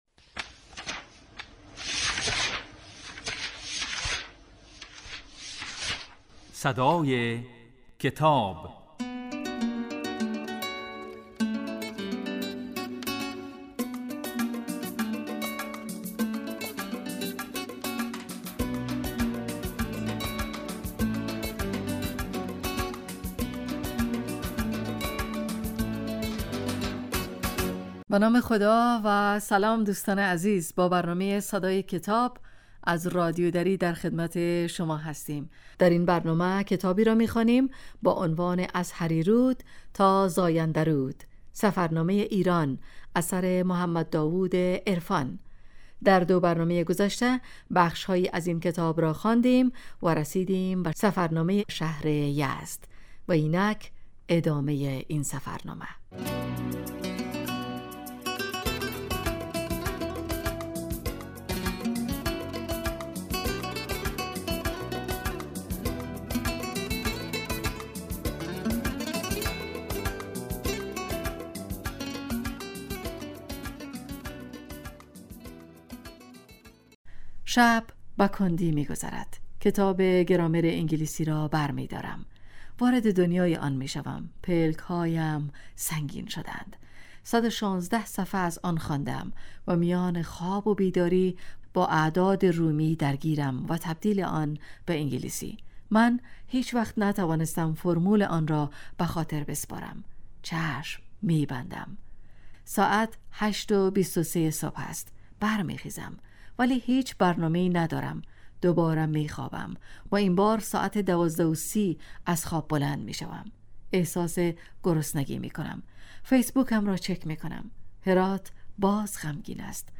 این برنامه کتاب صوتی است و در روزهای سه شنبه و پنج شنبه در بخش صبحگاهی پخش و در بخش نیمروزی بازپخش می شود.